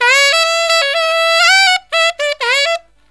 63SAXMD 11-R.wav